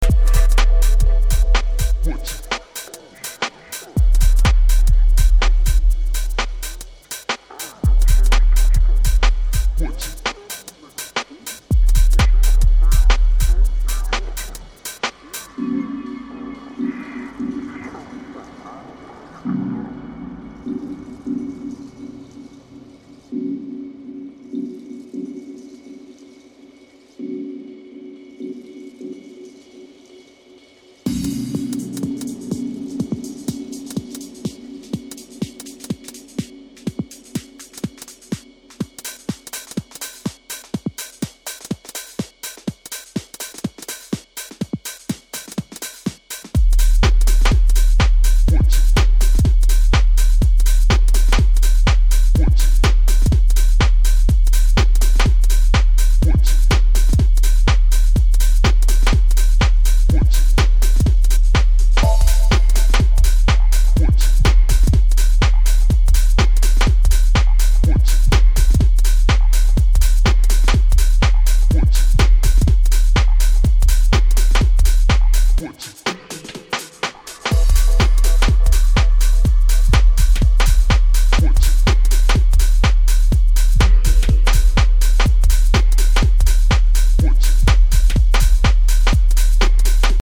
Playful house tracks